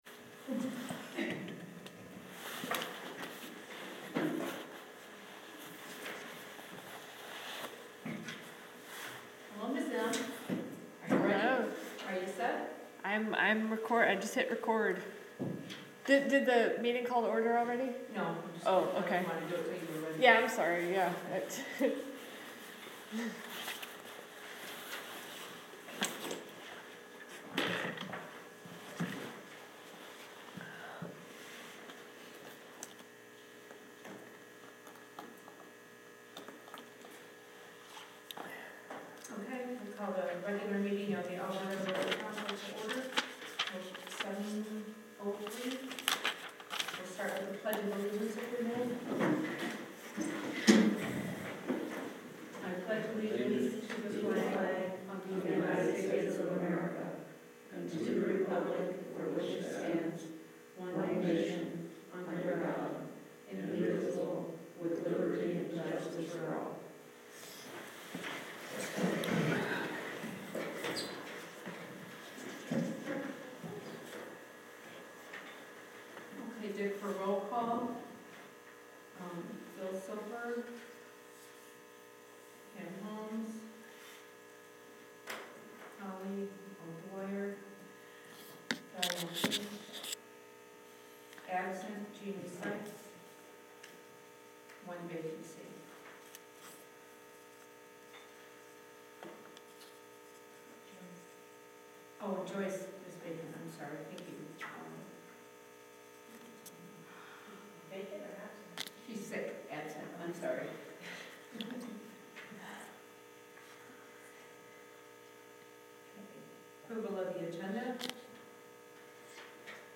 VILLAGE OF ELBERTA BOARD OF TRUSTEES REGULAR MEETING